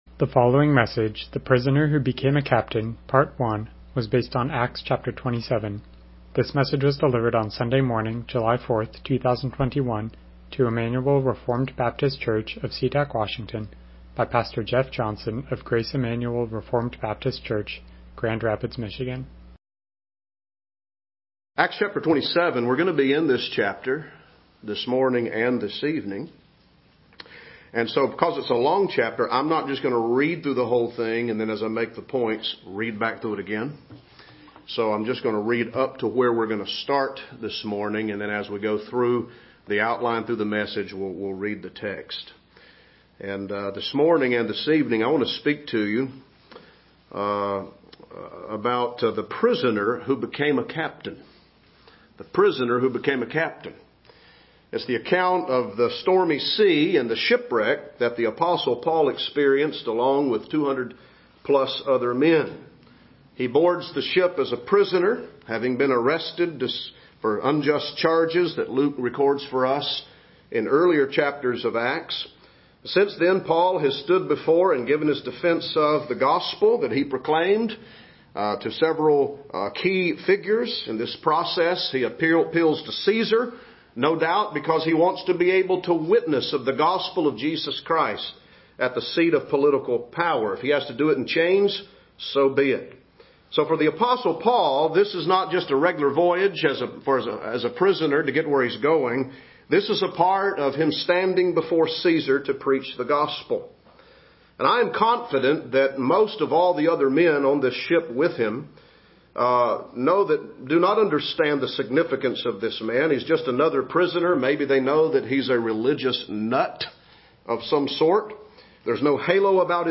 Passage: Acts 27 Service Type: Morning Worship « To the Seven Churches The Prisoner Who Became A Captain